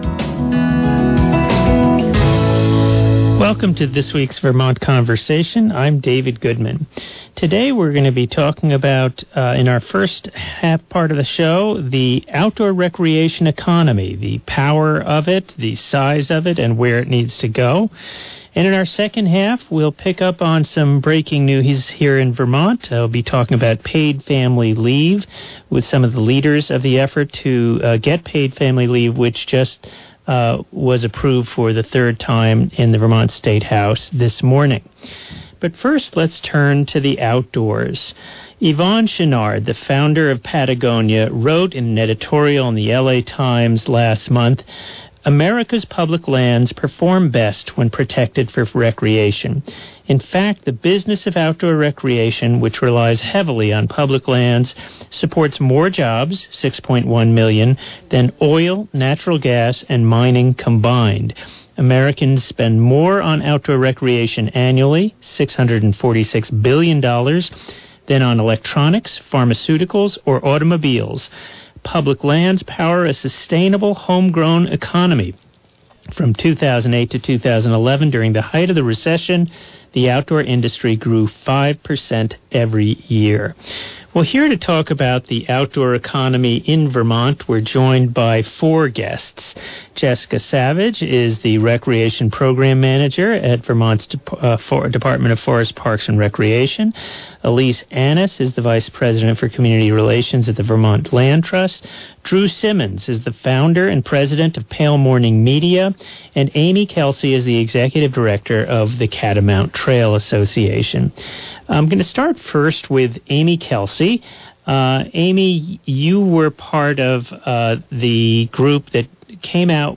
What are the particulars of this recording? We talk with four leaders in the sector about the outdoor industry, land conservation, and the next big thing in recreation. (May 3, 2017 broadcast)